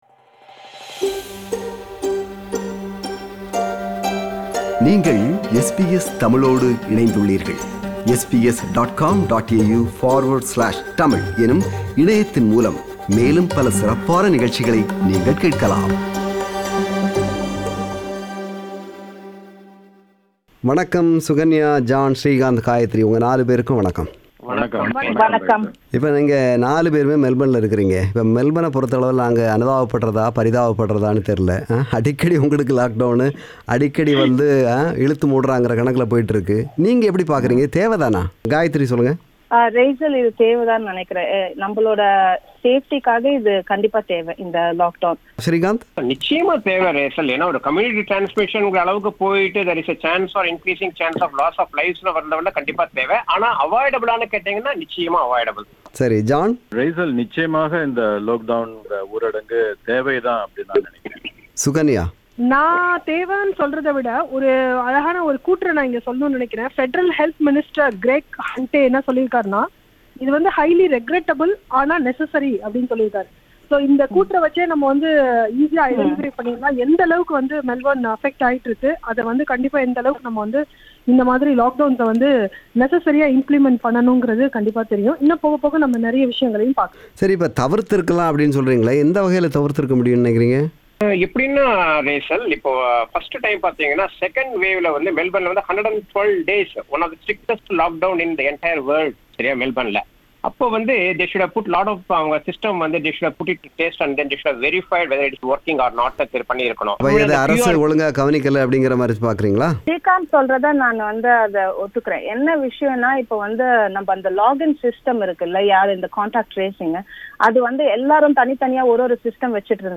மெல்பனும், முடக்கநிலையும், நம்மவர்களும்: ஒரு கலந்துரையாடல்